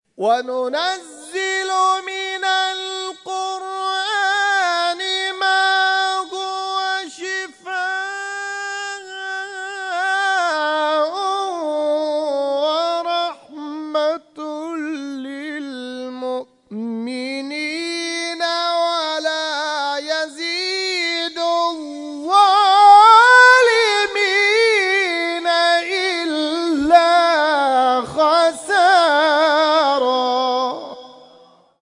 در ادامه قطعات تلاوت این کرسی‌های تلاوت ارائه می‌شود.